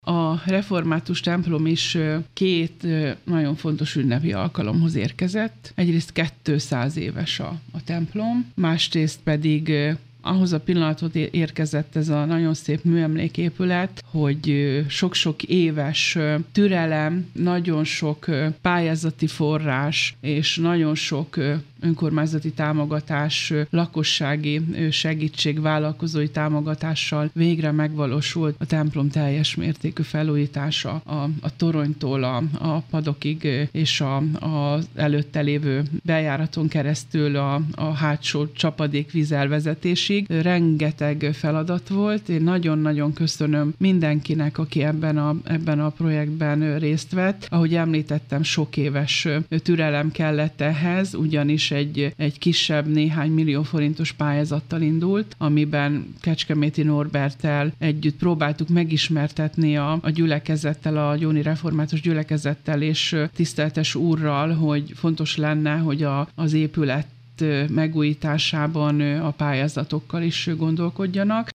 Pálinkásné Balázs Tünde alpolgármestert hallják: